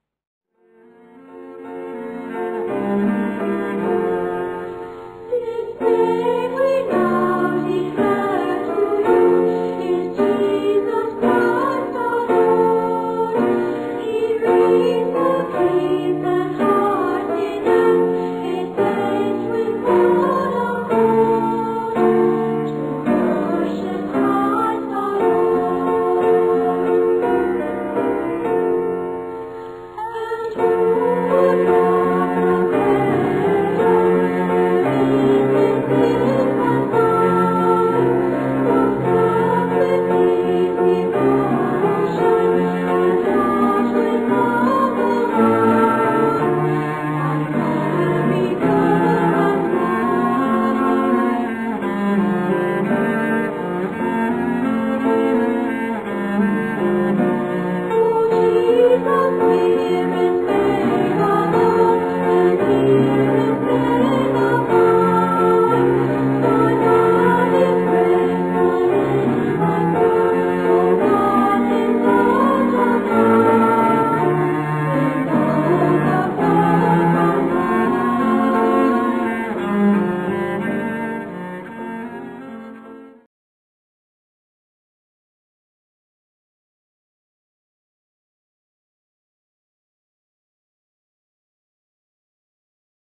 Voicing: Unison Treble